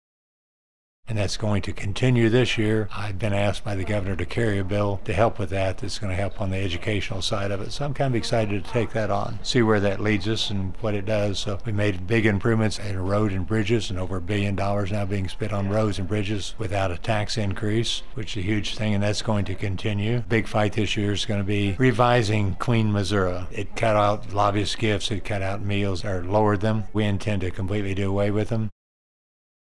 2. Senator Cunningham adds there is lots of legislation to come.